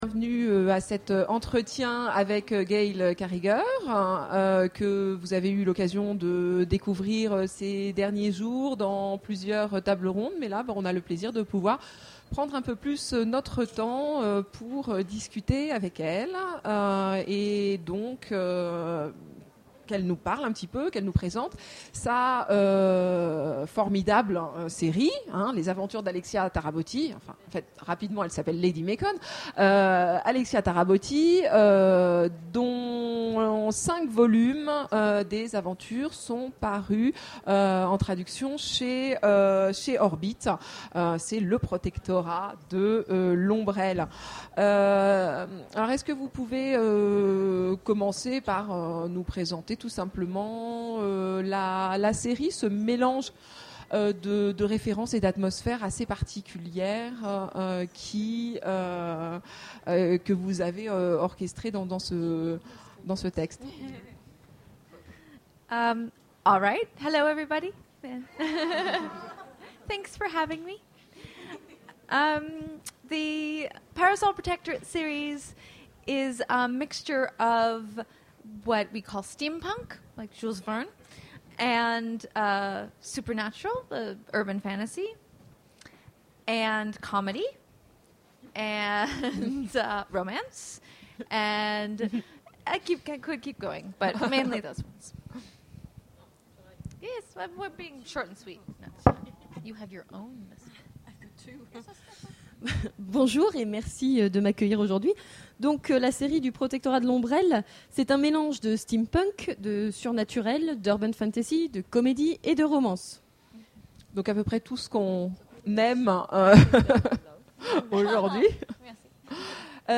Imaginales 2013 : Conférence Entretien avec... Gail Carriger
- le 31/10/2017 Partager Commenter Imaginales 2013 : Conférence Entretien avec... Gail Carriger Télécharger le MP3 à lire aussi Gail Carriger Genres / Mots-clés Rencontre avec un auteur Conférence Partager cet article